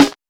SNARE.71.NEPT.wav